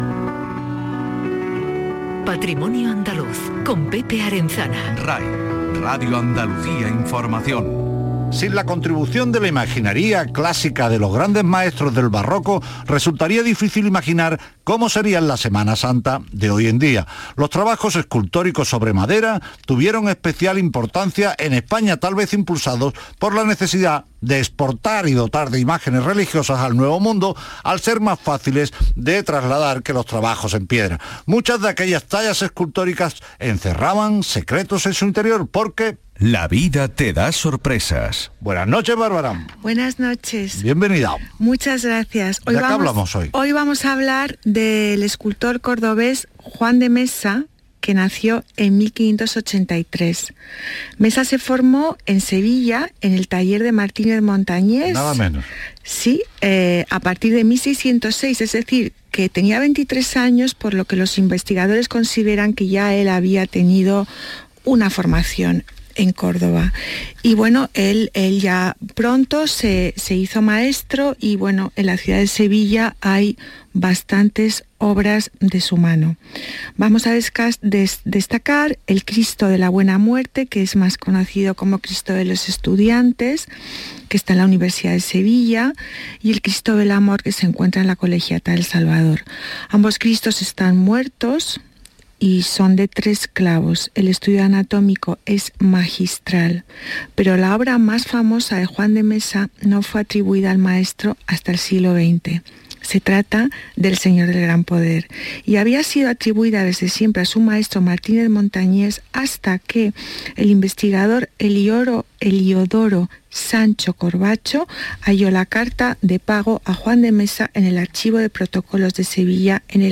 Aquí os dejo mi intervención en el programa de Radio Andalucía Información, «Patrimonio andaluz» del día 10/04/2022.